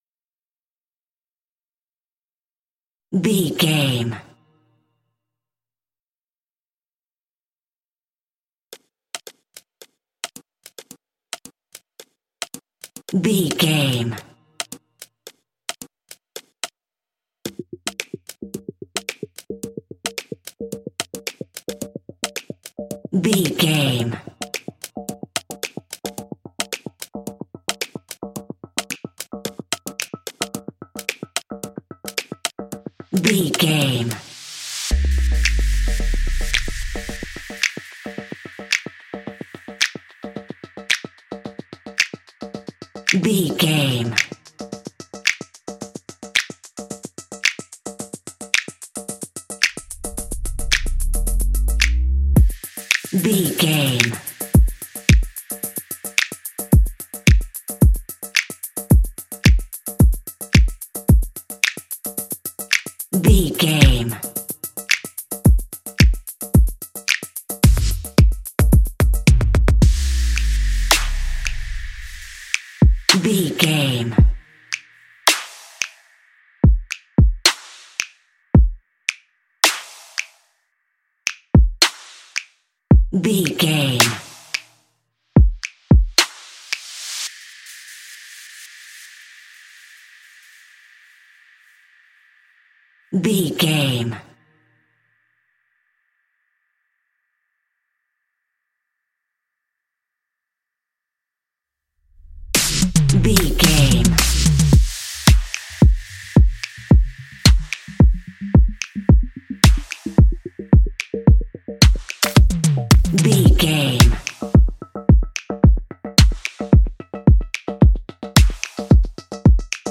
Ionian/Major
bouncy
energetic
percussion
drum machine